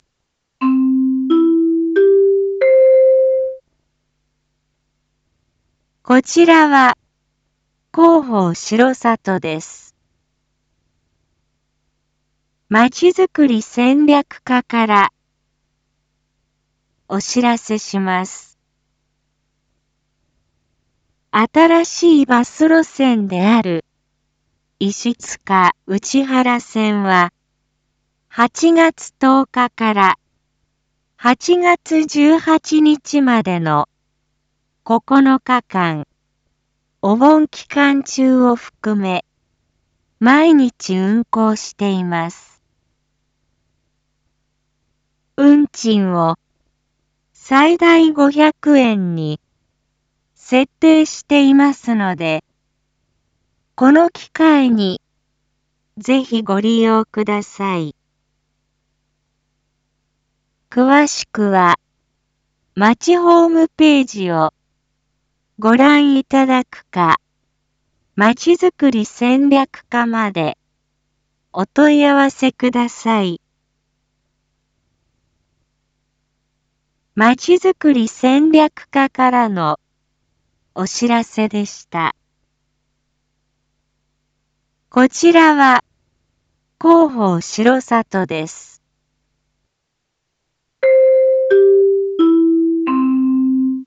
一般放送情報
Back Home 一般放送情報 音声放送 再生 一般放送情報 登録日時：2024-08-09 19:01:35 タイトル：①石塚・内原線のお盆期間の運行について インフォメーション：こちらは広報しろさとです。